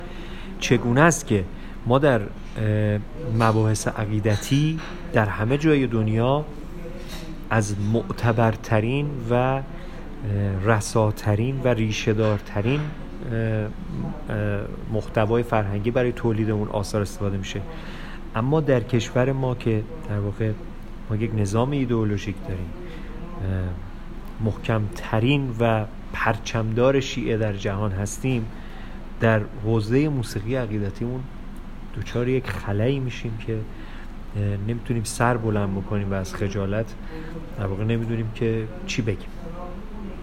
گفت‌وگو با ایکنا